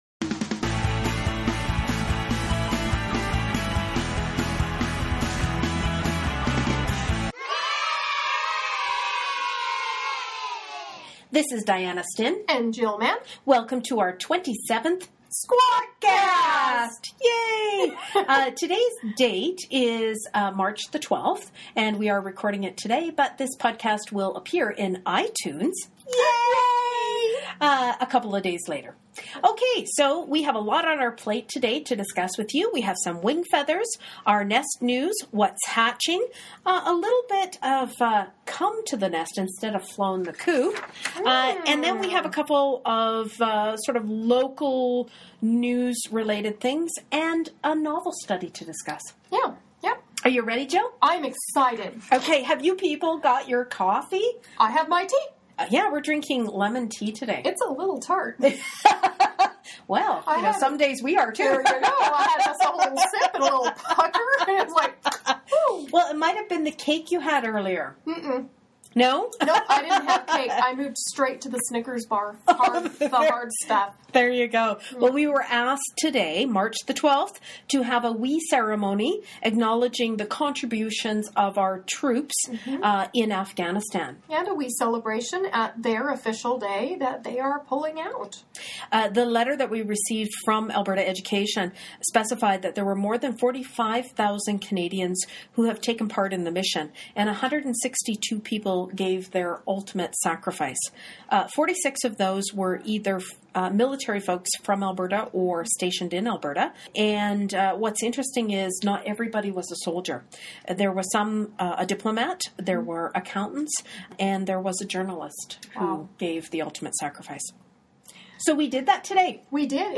Welcome to our squawkcast – join two old hens